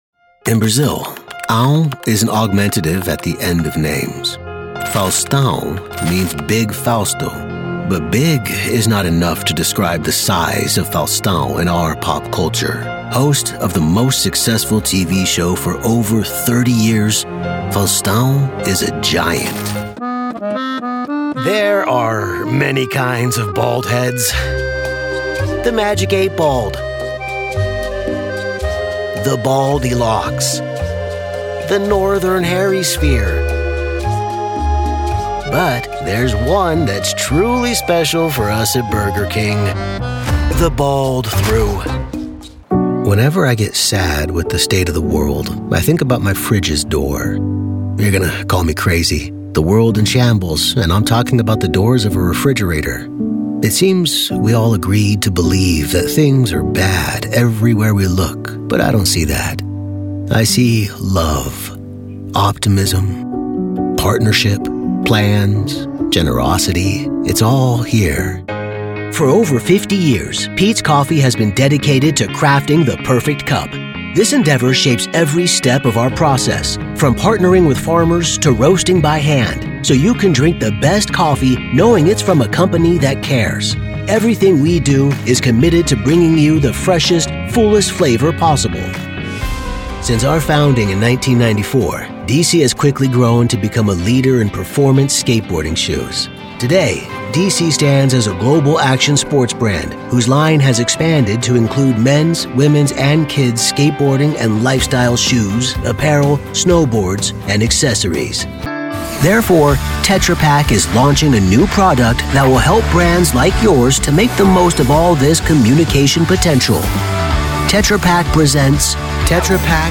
Corporate & Industrials
Middle Aged
Warm | Trustworthy | Authoritative